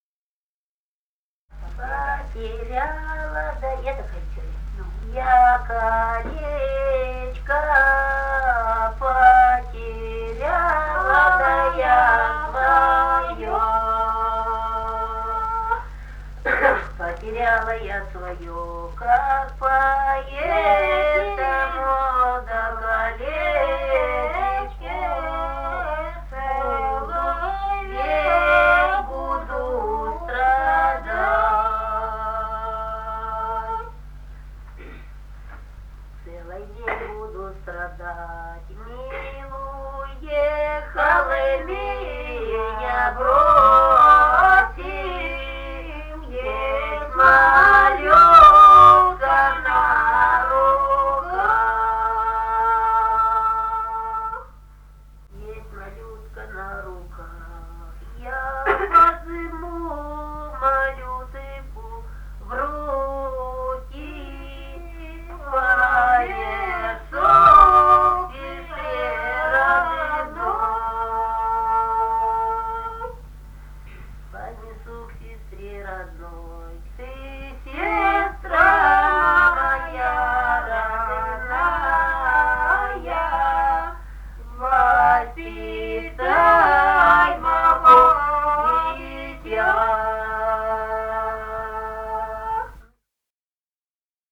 Русские народные песни Красноярского края.
«Потеряла да я колечко» (лирическая). с. Тасеево Тасеевского района.